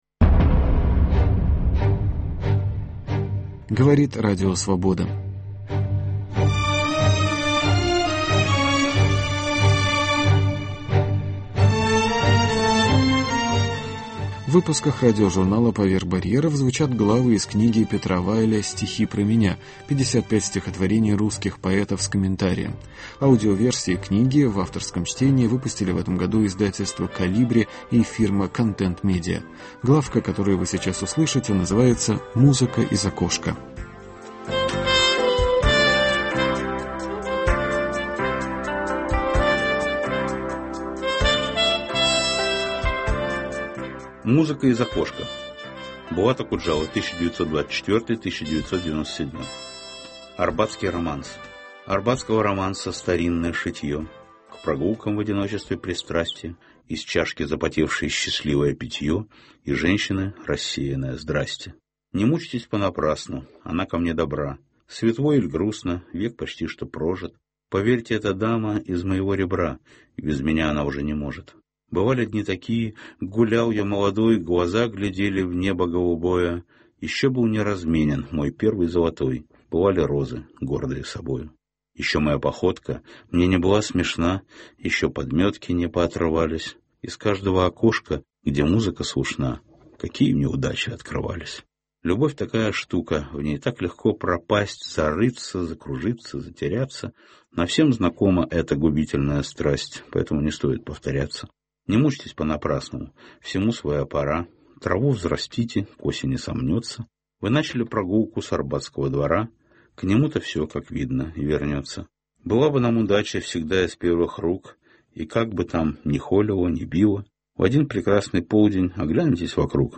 Петр Вайль читает главу из своей книги «Стихи про меня»: Музыка из окошка